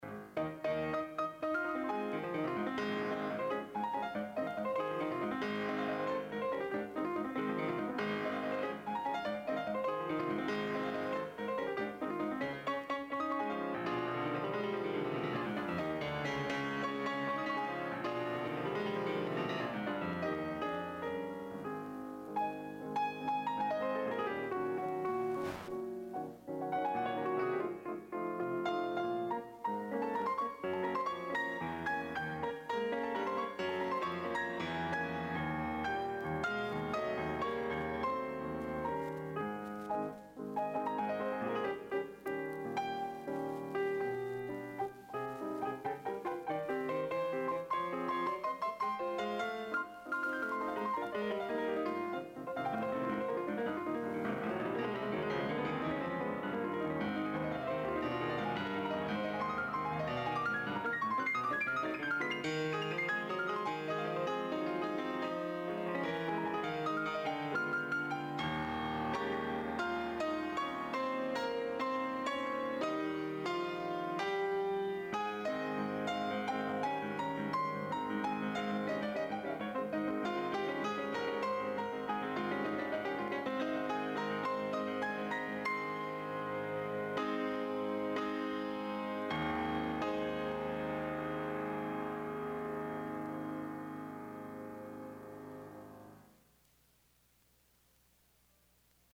Additional Date(s)Recorded May 20, 1981 in the Ed Landreth Hall, Texas Christian University, Fort Worth, Texas
Suites (Piano)
Short audio samples from performance